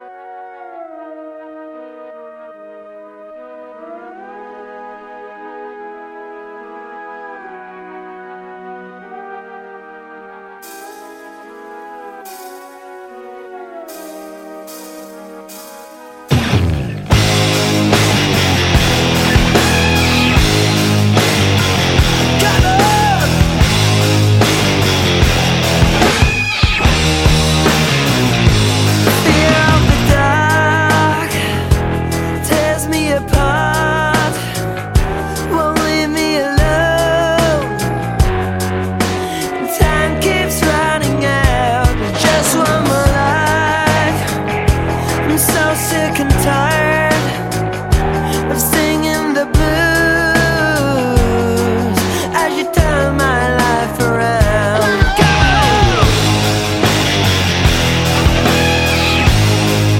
Рок
альтернативный поп-рок.